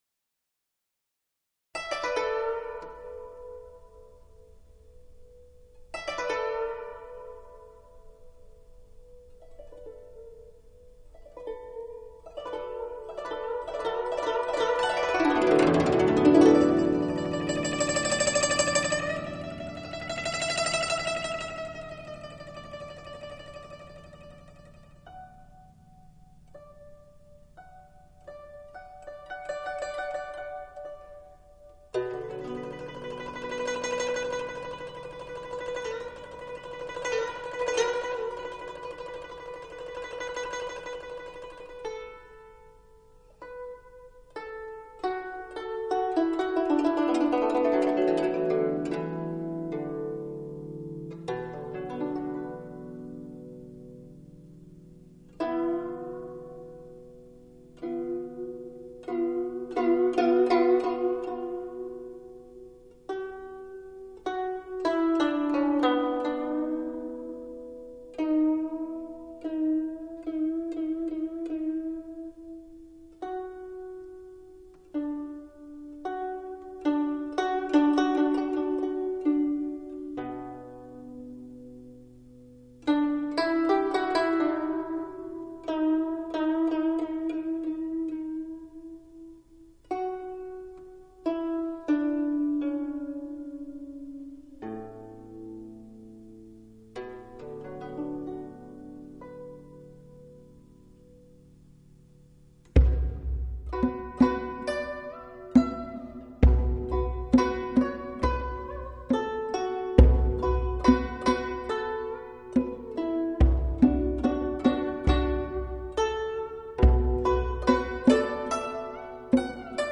箏與象腳鼓
象腿鼓